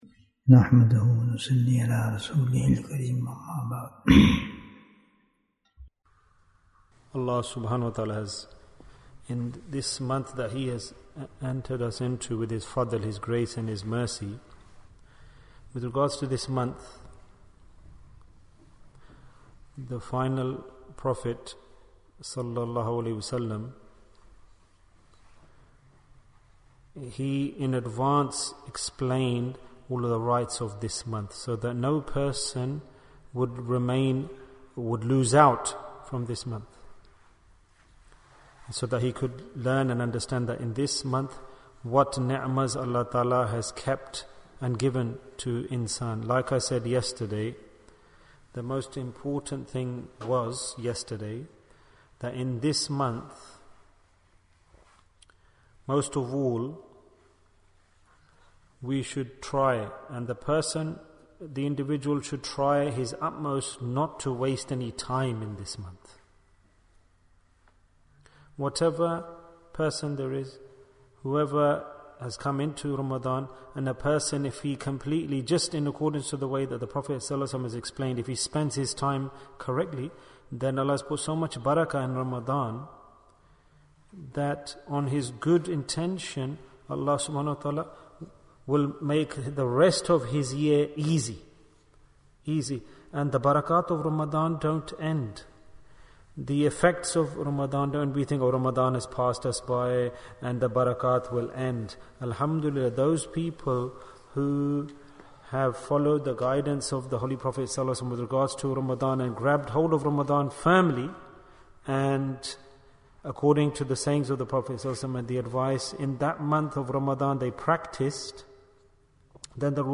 The Cure for Worries in Ramadhan Bayan, 35 minutes27th March, 2023